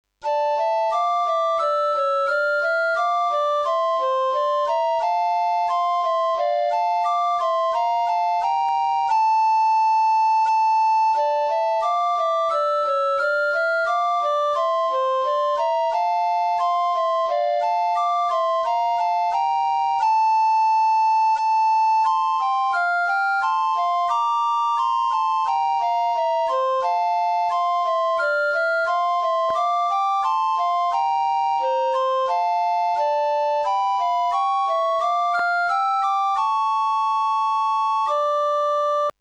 • für 2 Sopranblockflöten
Klangbeispiel